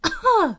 peach_coughing3.ogg